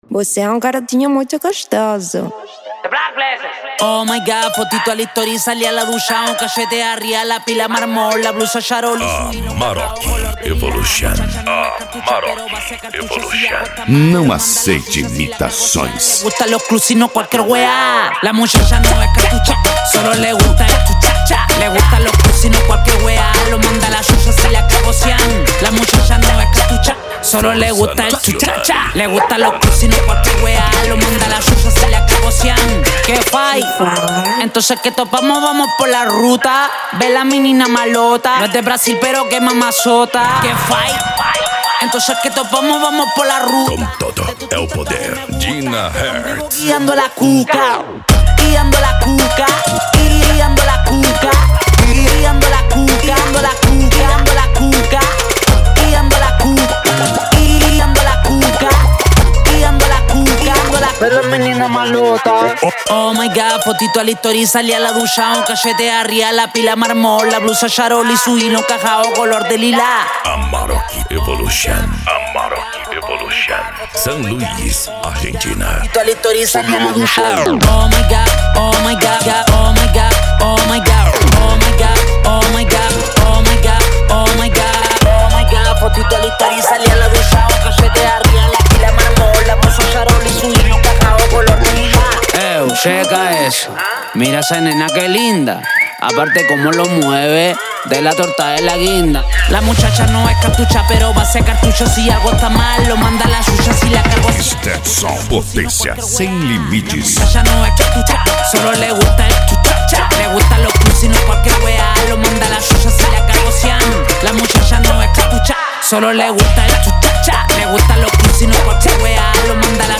Funk
Remix